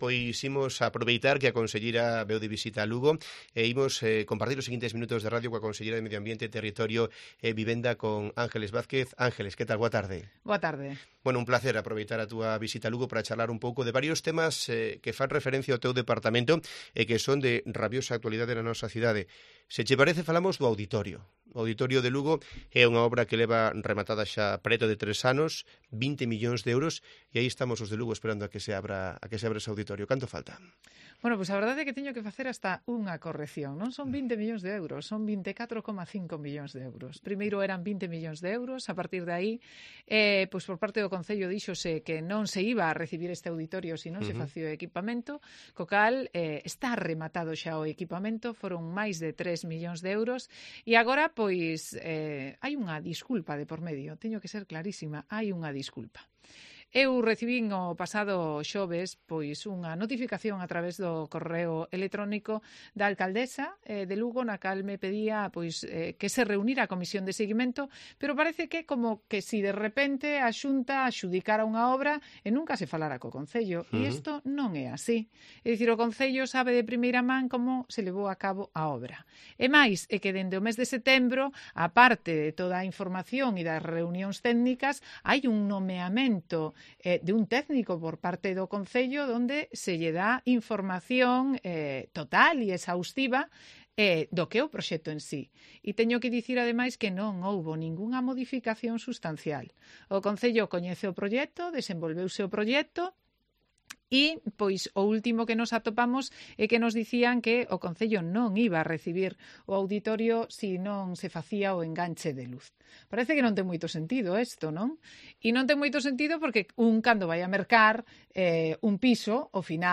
Escucha la entrevista completa a la conselleira de Medio Ambiente en Cope Lugo